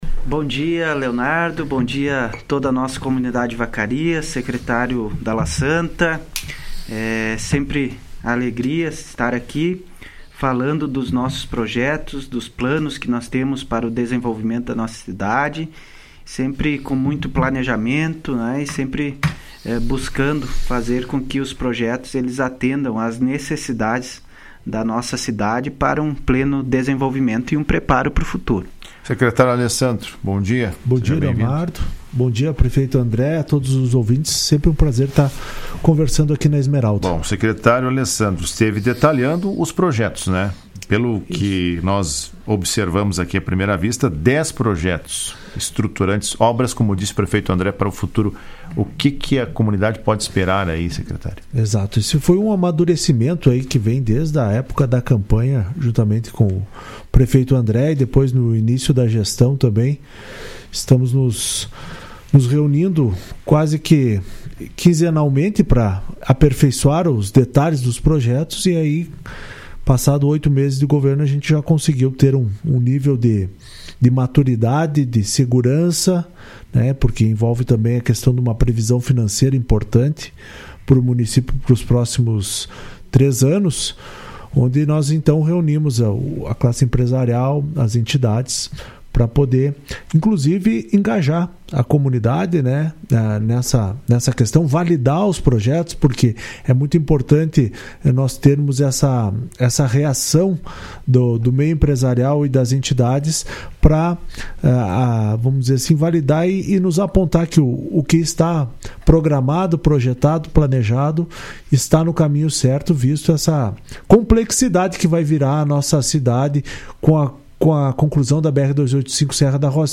São pelo menos 10 projetos que interferem na realidade atual do município , segundo o prefeito André Rokoski e o secretário de planejamento, Alessandro Dalassanta, que participaram do programa Comando Geral desta quinta-feira.